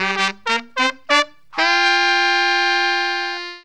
HORN RIFF 12.wav